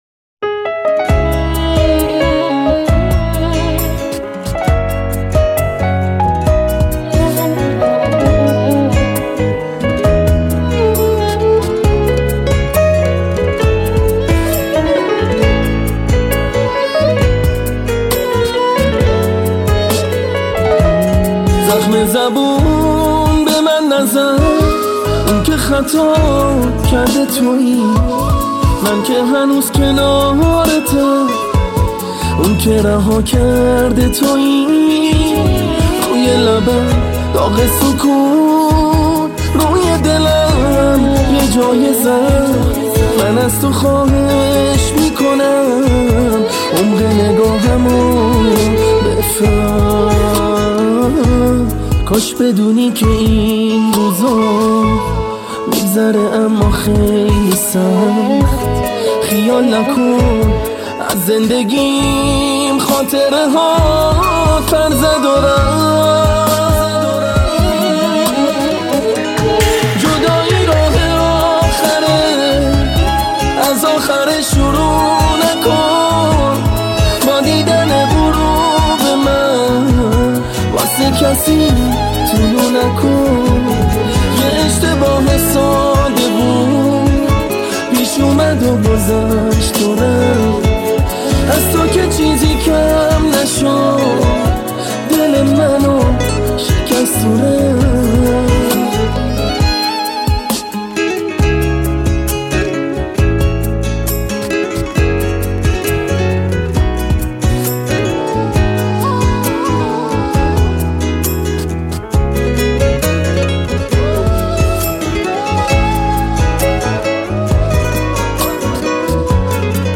موسیقی شاد بندری می باشد به نام های